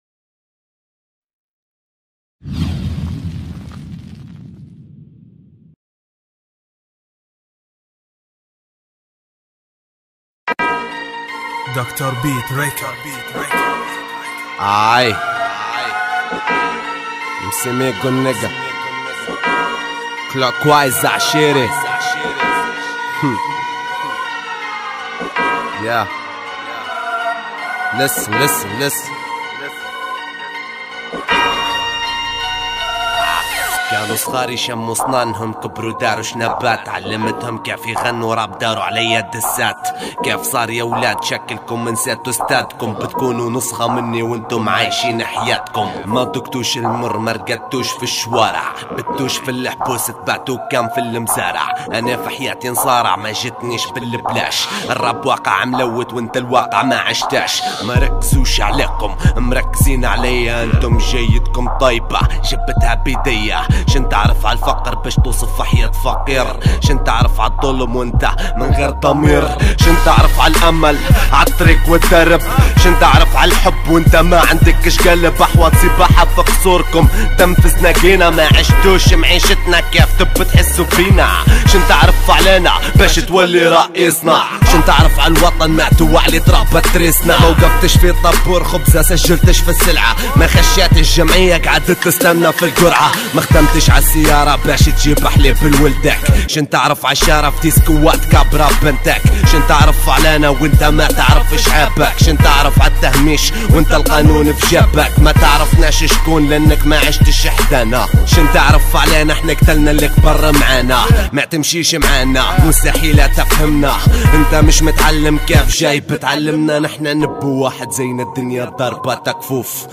جديد الراب الليبي